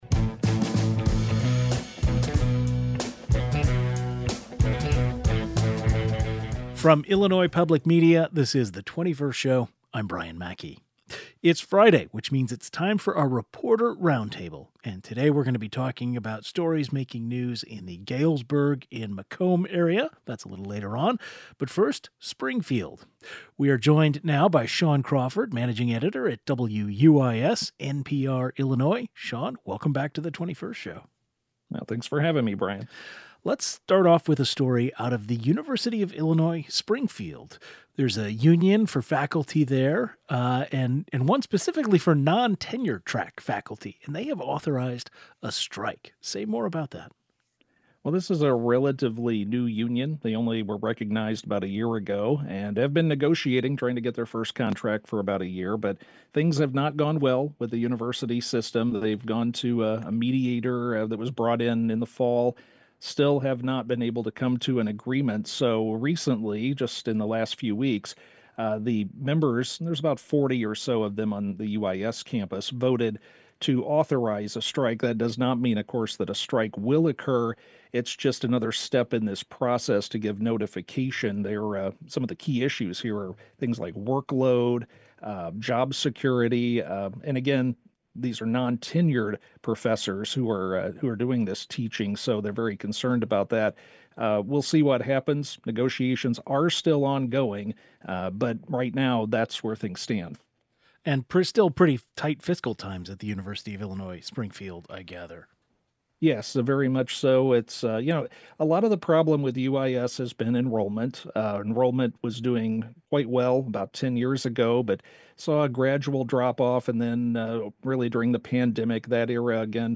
It's Friday, which means it's time for our Reporter Roundtable. On March 7, we talked about stories in the Galesburg and Macomb area and the Springfield area.